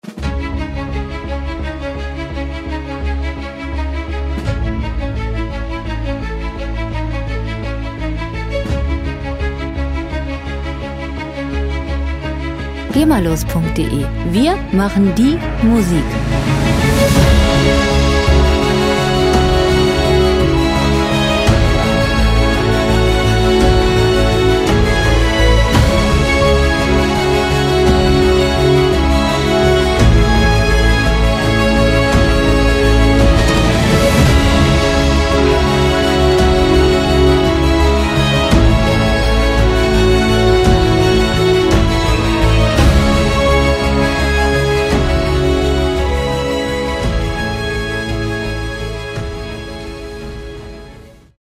Filmmusik - Abenteuer
Musikstil: Soundtrack
Tempo: 169 bpm
Tonart: C-Moll
Charakter: erhaben, überwältigend
Instrumentierung: Orchester, Chor, E-Piano, Synth-Bass